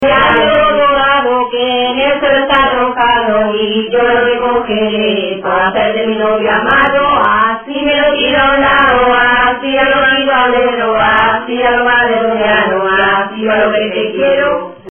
Materia / geográfico / evento: Canciones de corro Icono con lupa
Arenas del Rey (Granada) Icono con lupa
Secciones - Biblioteca de Voces - Cultura oral